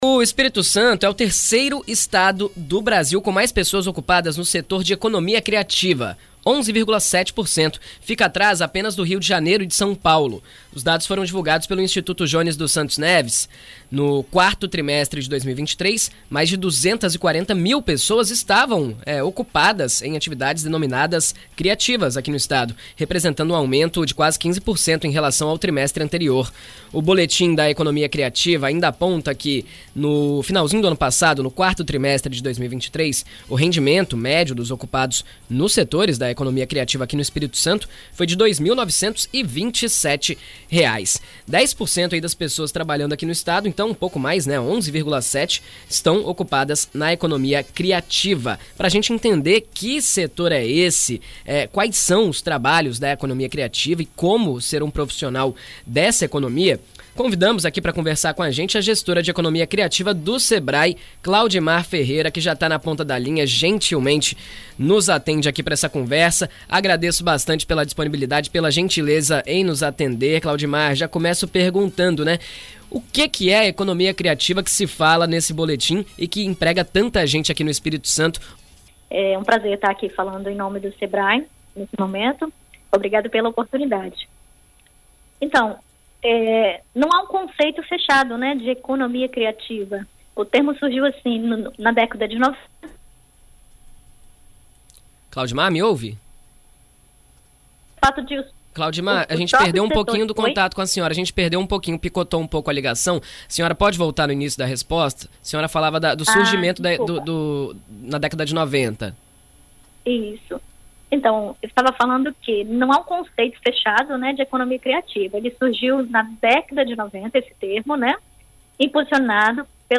Em entrevista à BandNews FM ES nesta segunda-feira